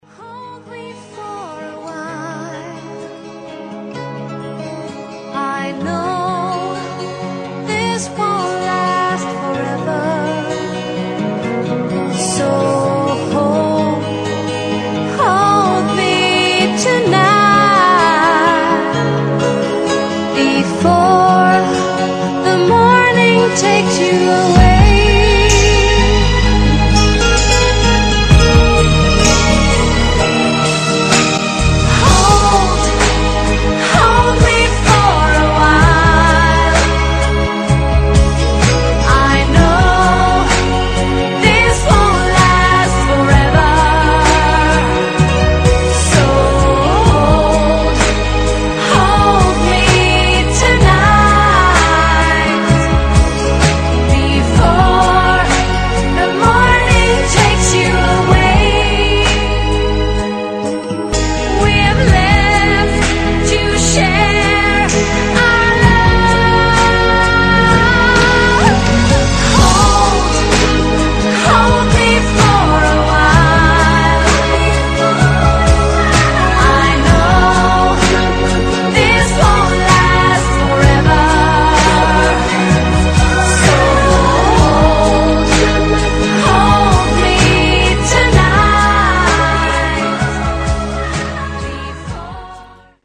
женский вокал
спокойные
медленные
баллада
Очень романтичный рингтон